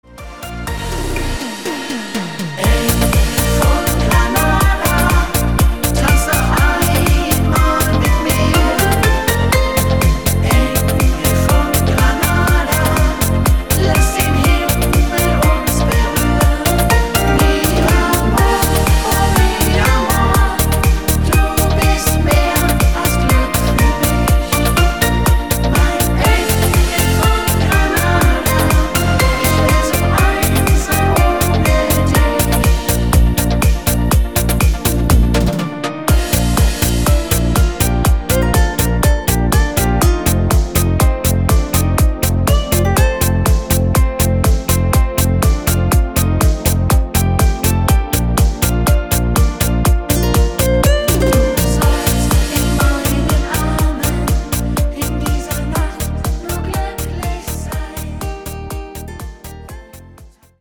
Rhythmus  Discofox